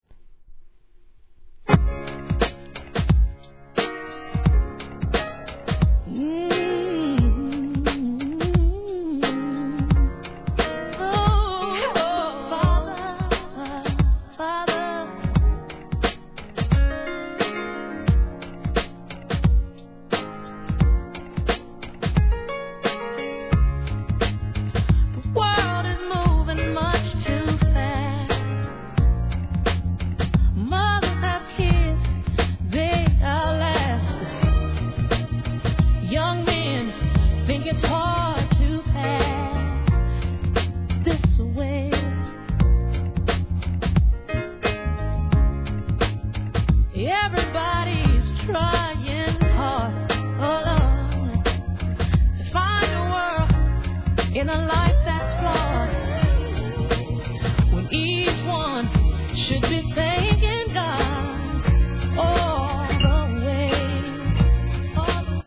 1. REGGAE